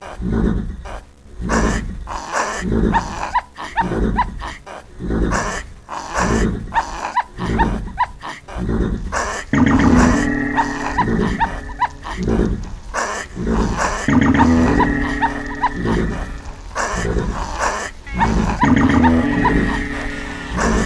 MONKEY horse mayhem clip
Category 🐾 Animals
beluga-whale horse loop monkey sound effect free sound royalty free Animals